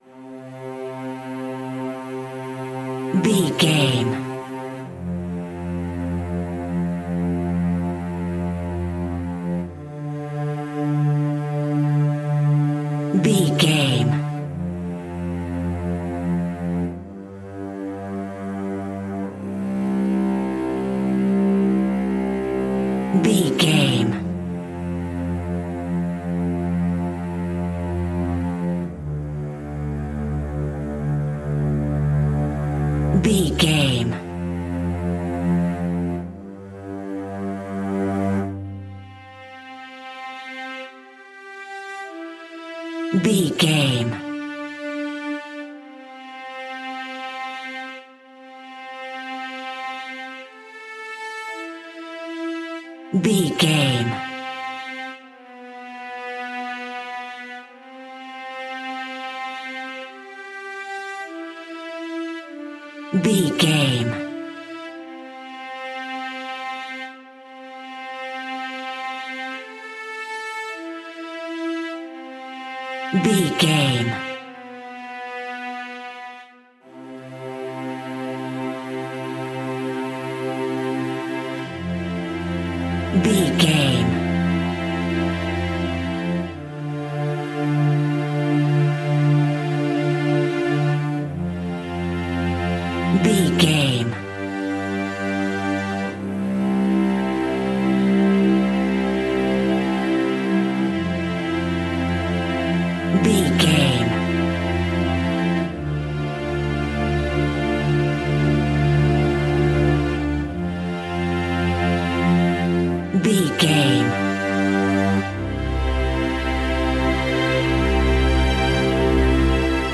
Uplifting
Ionian/Major
cello
double bass
violin
orchestra